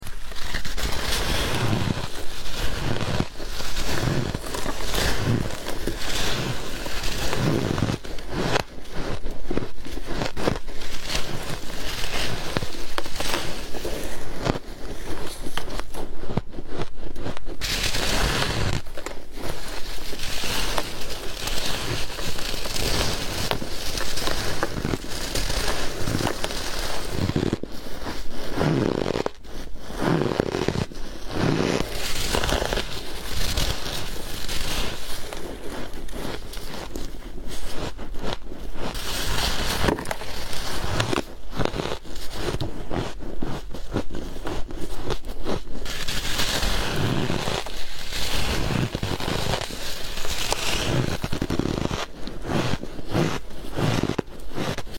Soft Ice Eating Asmr 💛 Sound Effects Free Download